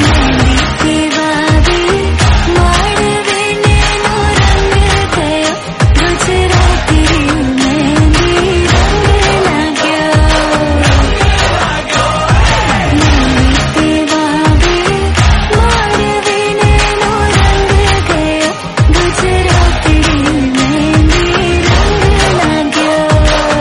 Hindi Ringtones